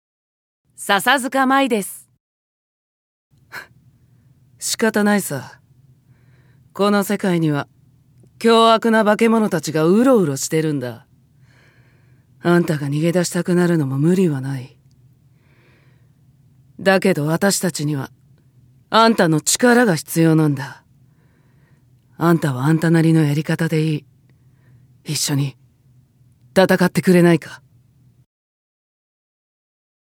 ◆名乗り＋クールな女戦士◆